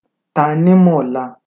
Captions English Audio Pronounciation